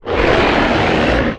horror
Demon Growl 3